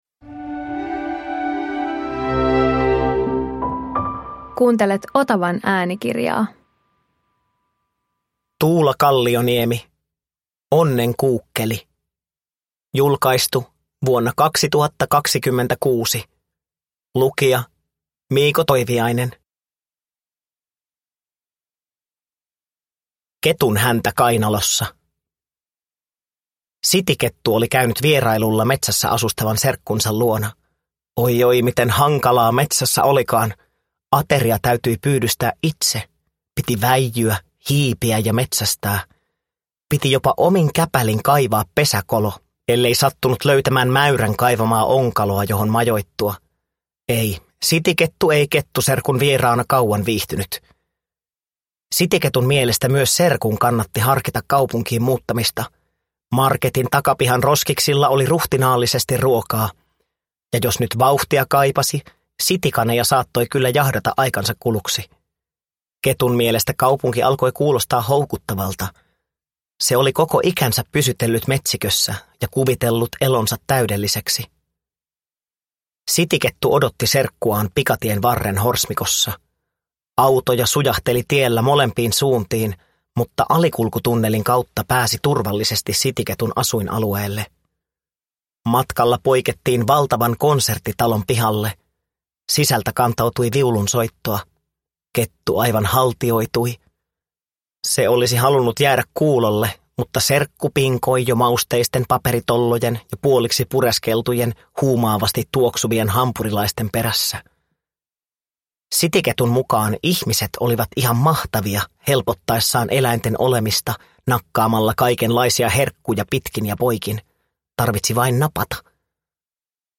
Onnenkuukkeli – Ljudbok